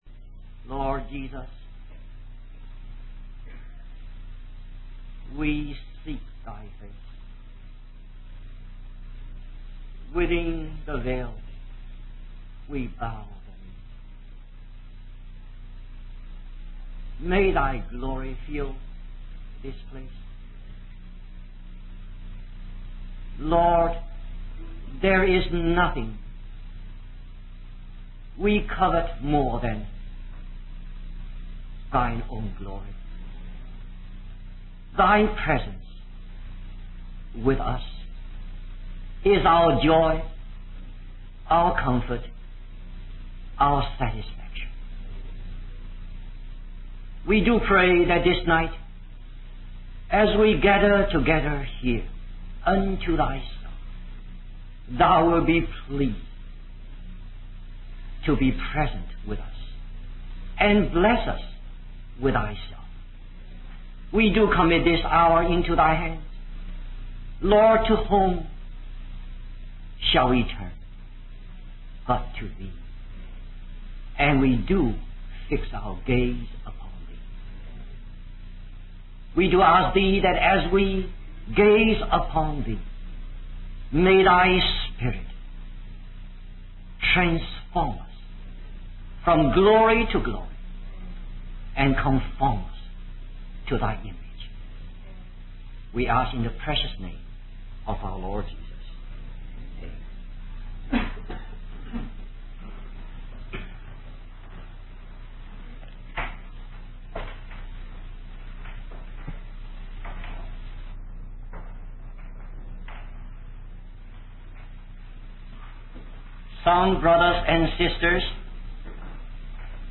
In this sermon, the preacher reflects on the suffering of Jesus on the cross and the purpose behind it. He emphasizes that Jesus willingly endured immense suffering to atone for the sins of humanity.